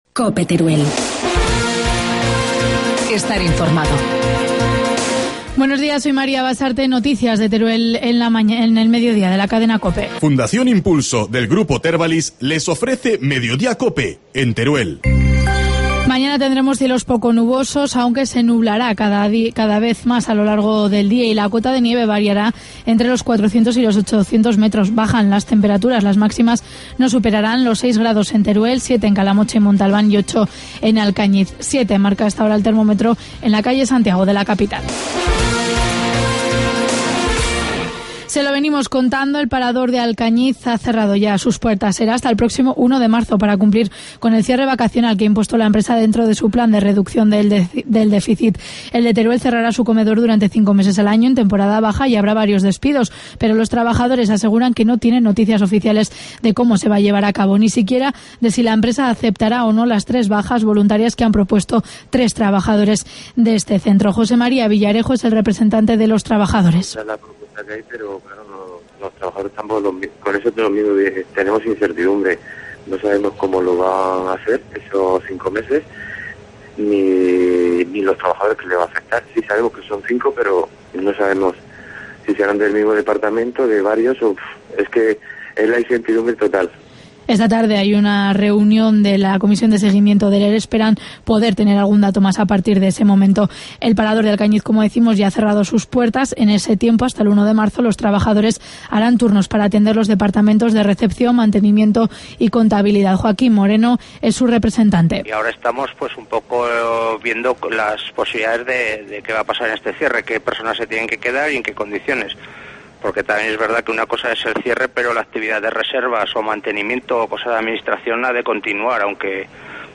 Informativo mediodía, lunes 21 de enero